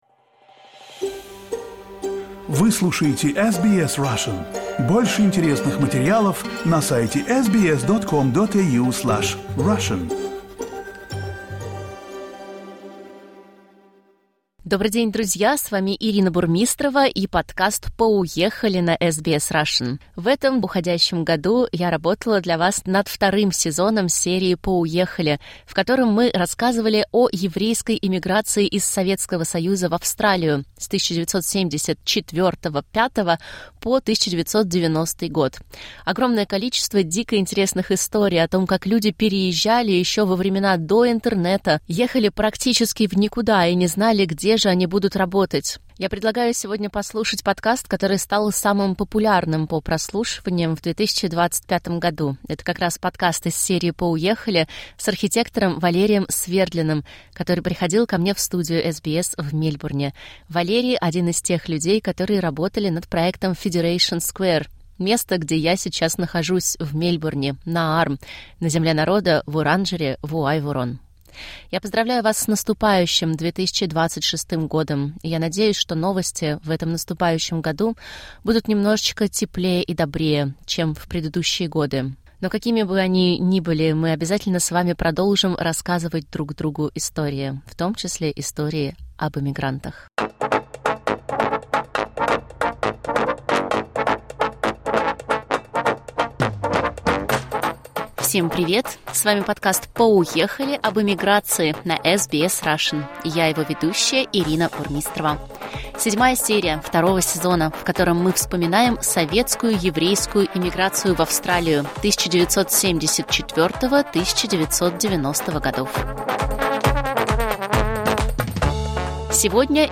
В конце года мы вспоминаем любимые интервью 2025.